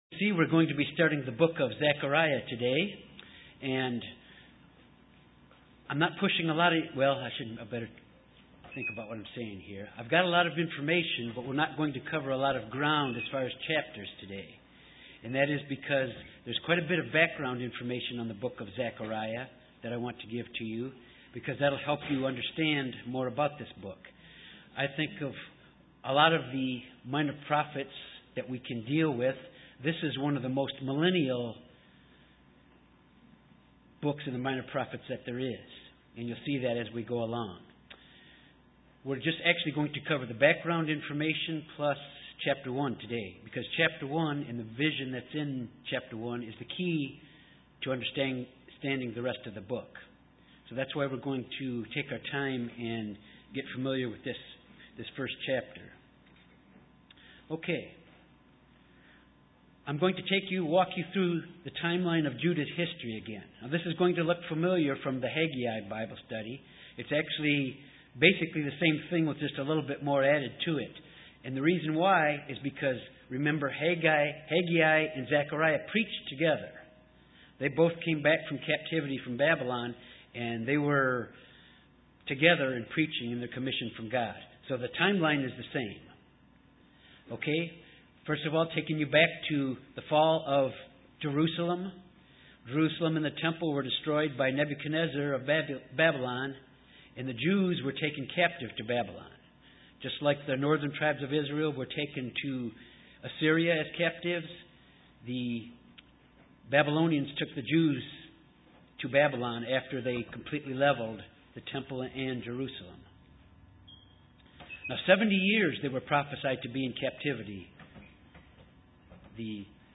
Bible study on Zechariah, introduction and chapter one: The purpose of this book was to motivate the people to rebuild the temple and Jerusalem, and to give hope for the future. Chapter one goes through the first and second vision's of Zechariah.
UCG Sermon Studying the bible?